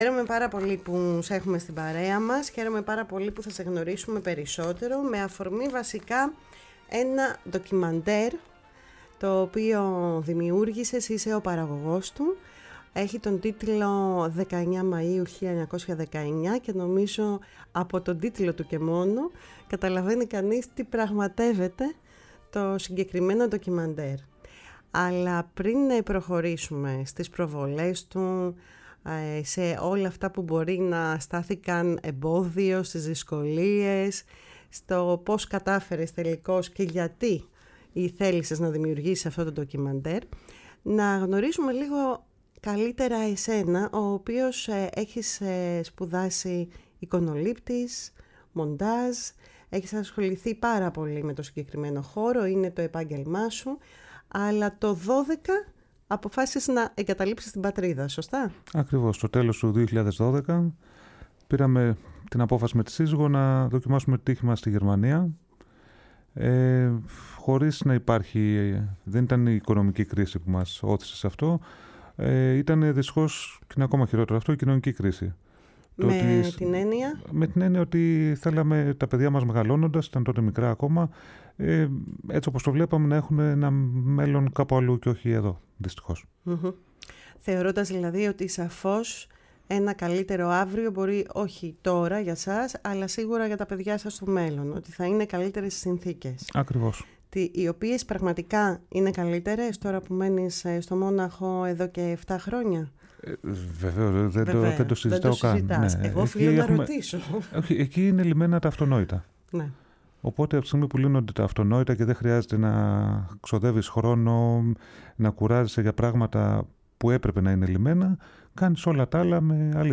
φιλοξενήθηκε στη “Φωνή της Ελλάδας” και συγκεκριμένα στην εκπομπή “Κουβέντες μακρινές”